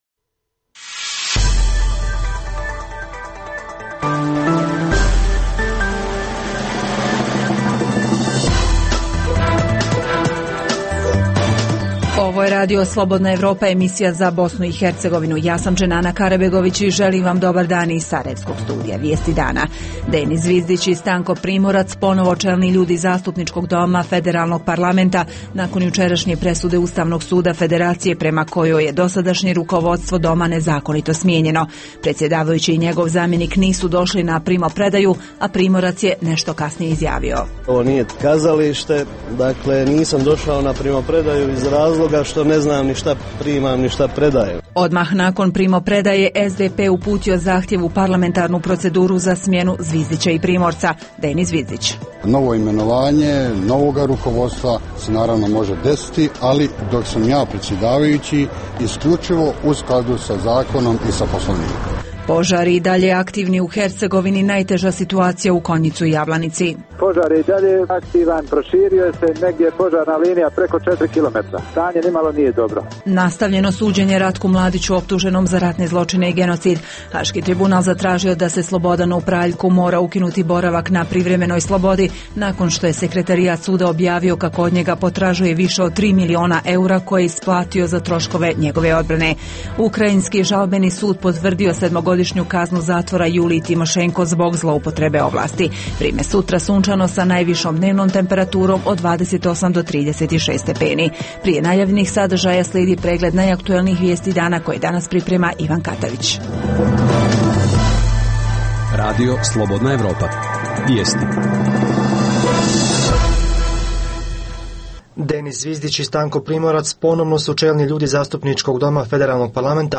Emisiju